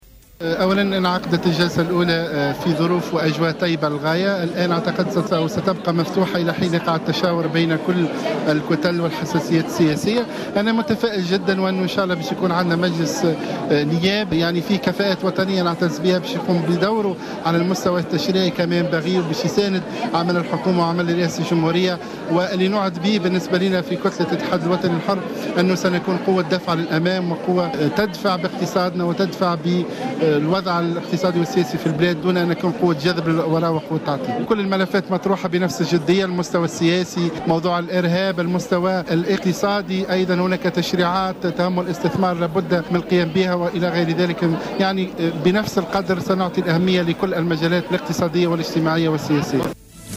أكد رئيس كتلة الاتحاد الوطني الحر،محسن حسن في تصريح ل"جوهرة أف أم" أن المشاورات انطلقت بين الكتل النيابية للتشاور بخصوص طريقة انتخاب رئيس مجلس نواب الشعب الجديد.